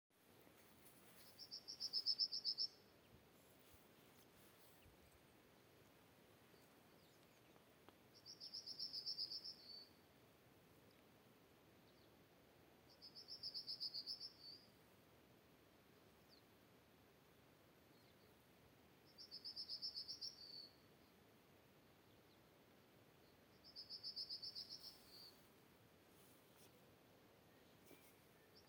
Birds -> Buntings ->
Yellowhammer, Emberiza citrinella
StatusVoice, calls heard